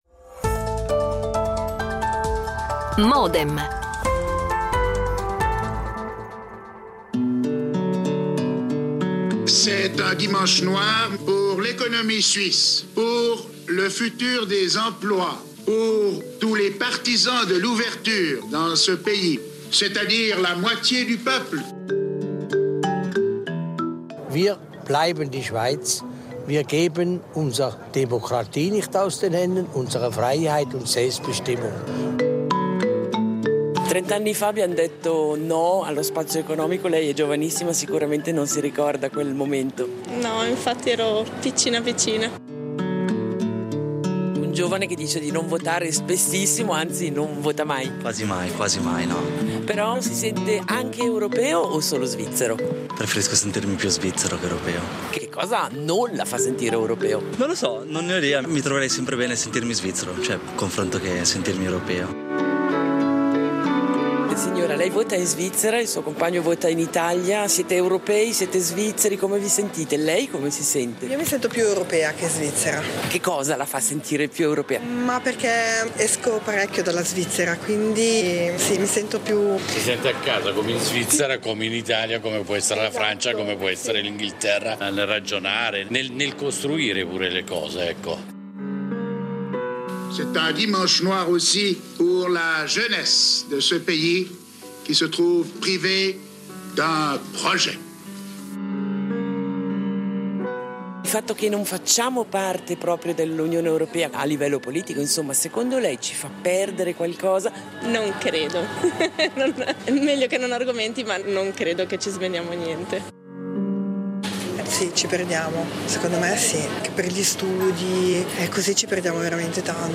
Quale il bilancio di questi 30 anni? E soprattutto quale futuro e quali intese sono ancora possibili fra Svizzera e Europa? Ne parliamo a Modem, con un faccia a faccia tra: MARCO CHIESA - consigliere agli Stati TI e presidente dell’UDC JON PULT – consigliere nazionale GR e vicepresidente del PSS Modem su Rete Uno alle 08:30, in replica su Rete Due alle 18:30.